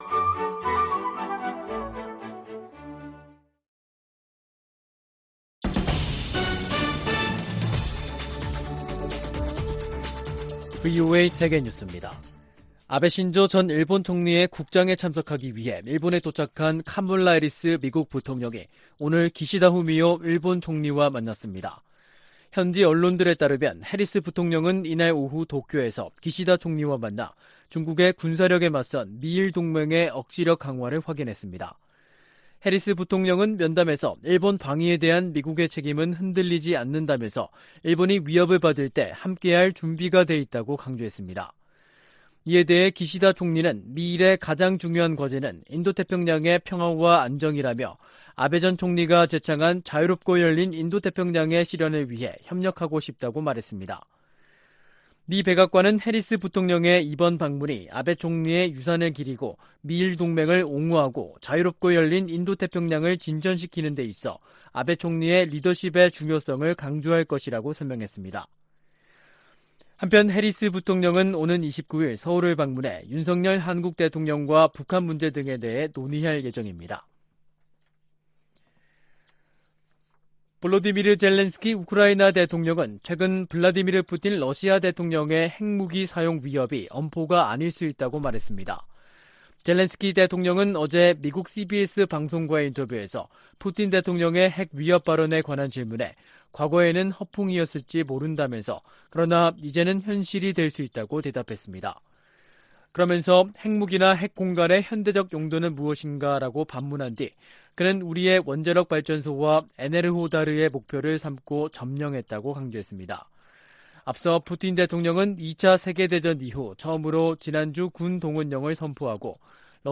VOA 한국어 간판 뉴스 프로그램 '뉴스 투데이', 2022년 9월 26일 3부 방송입니다. 한국 정부가 북한의 탄도미사일 도발을 규탄했습니다. 미 국무부도 북한 탄도미사일 발사를 비판하며 유엔 안보리 결의 위반임을 강조했습니다. 북한 신의주와 중국 단둥을 오가는 화물열차가 150일 만에 운행을 재개했습니다.